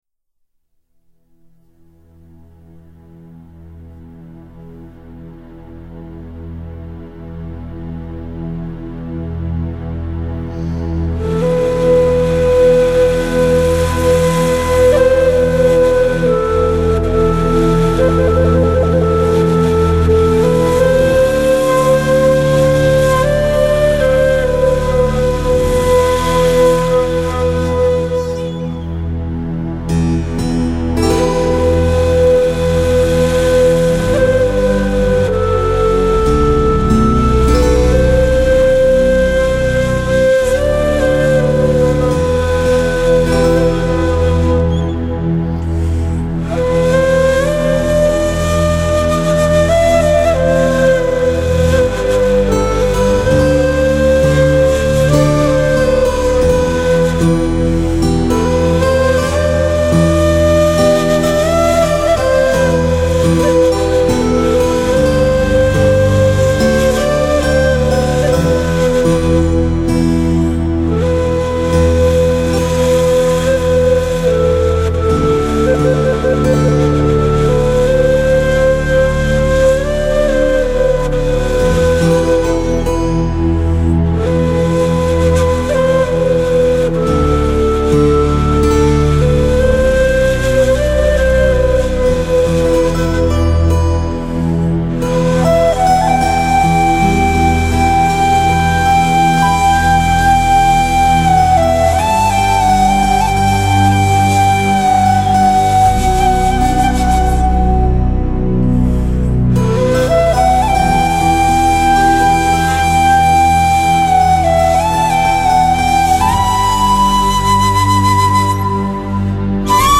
el Ney (flauta de caña).